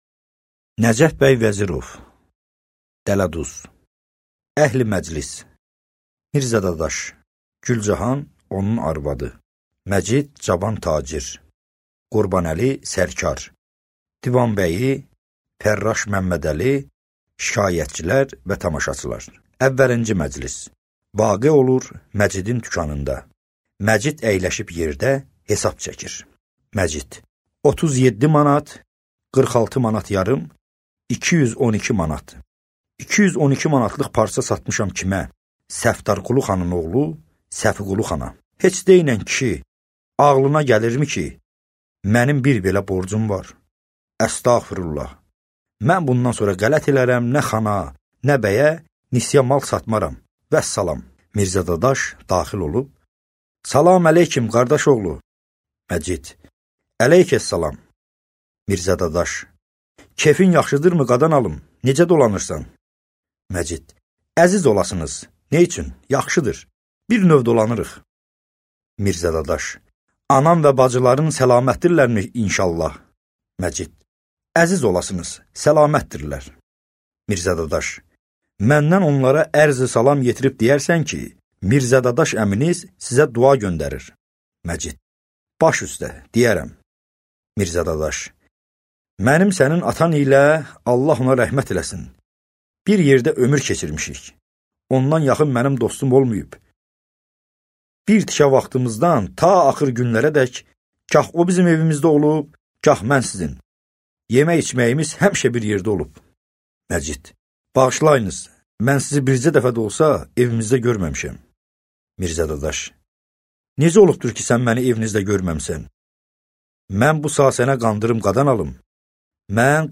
Аудиокнига Dələduz | Библиотека аудиокниг